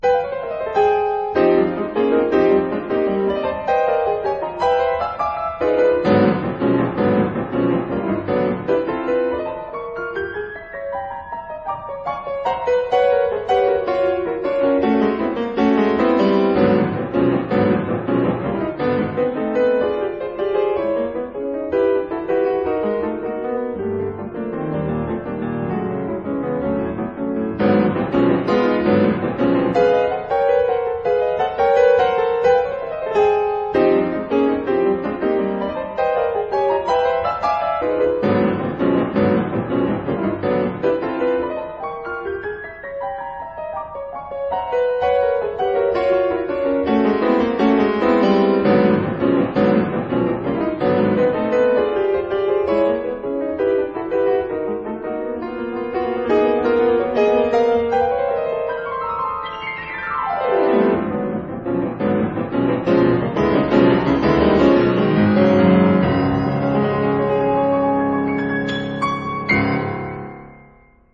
她呈現了一種百年累積後，又開了小花的香味。